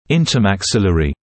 [ˌɪntəmæk’sɪlərɪ][ˌинтэмэк’силэри]межчелюстной